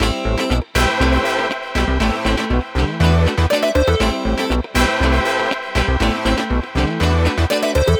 23 Backing PT3.wav